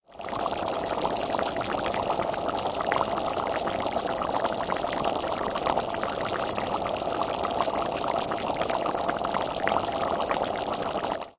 公鸡和沸腾的锅
描述：在我的祖父母厨房里 设备+骑行方向
标签： 鸡肉 油炸 HOR 国家 冒泡
声道立体声